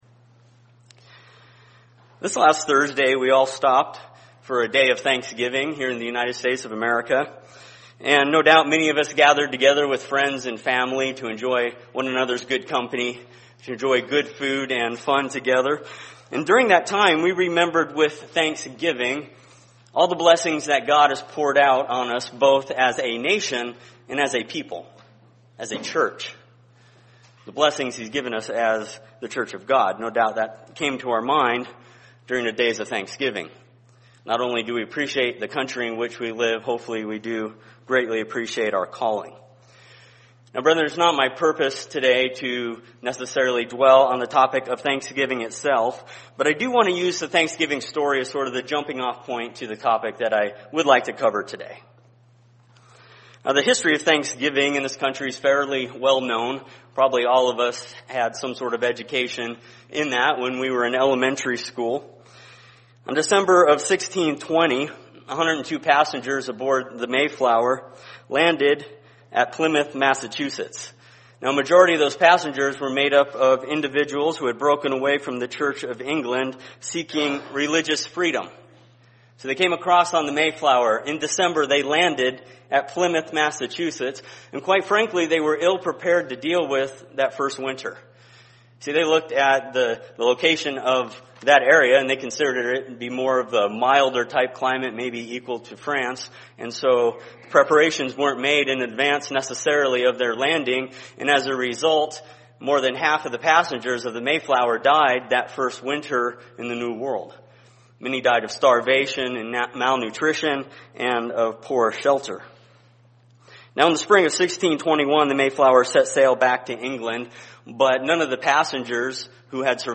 Sermon on Saturday, November 29, 2014 in Spokane, Washington When everything else fails, you can rely on God's promise - He is faithful.